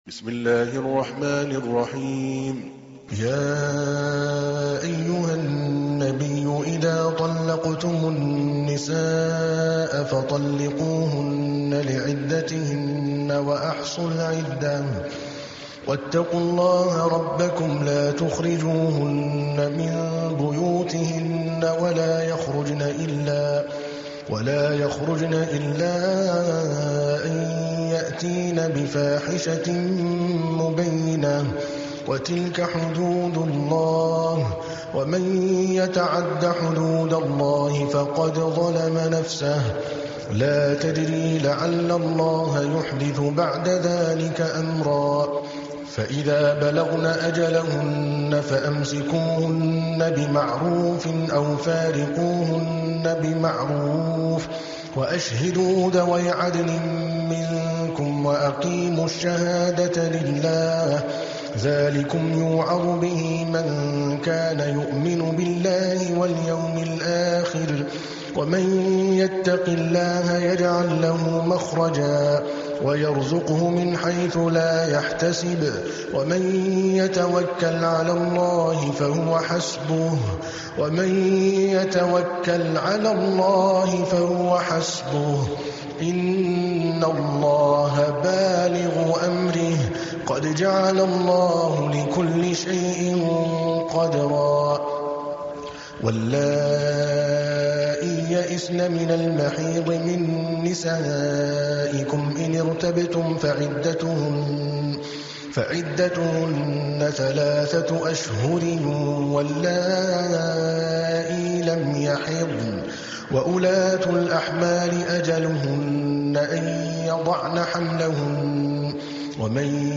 تحميل : 65. سورة الطلاق / القارئ عادل الكلباني / القرآن الكريم / موقع يا حسين